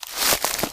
STEPS Bush, Walk 17.wav